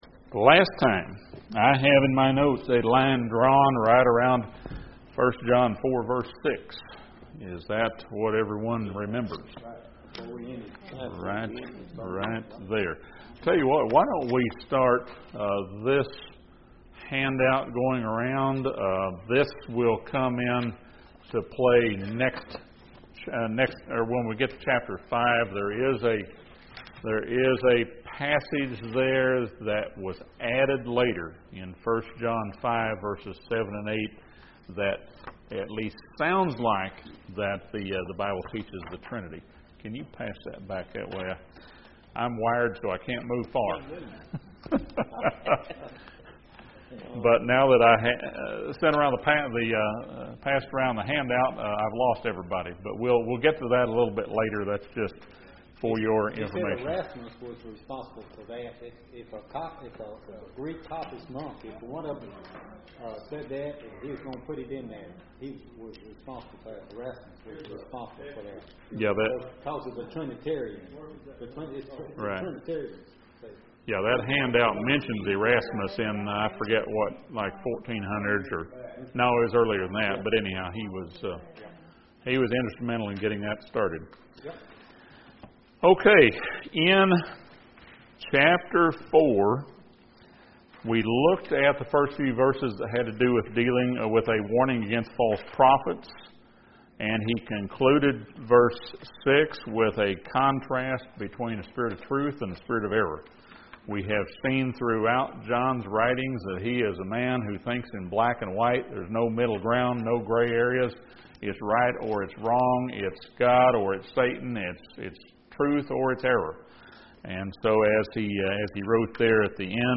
This Bible study surveys the last two chapters of 1 John.